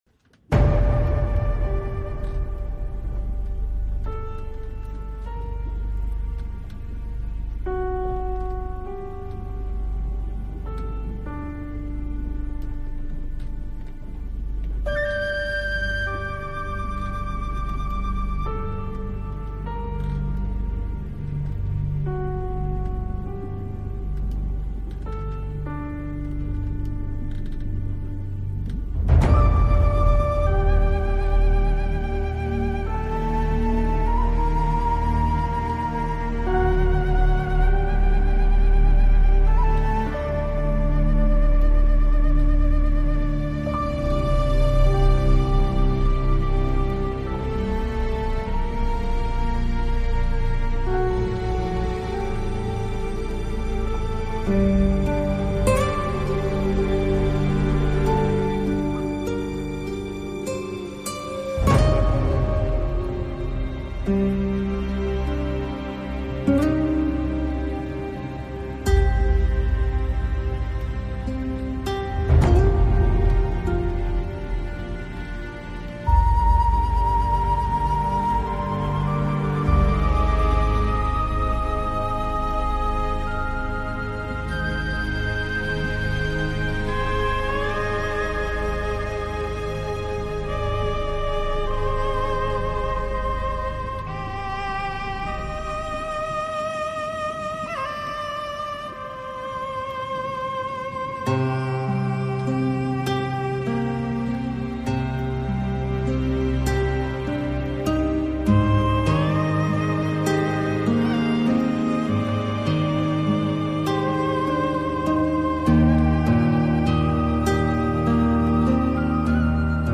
CALM AMBIENT MUSIC MIX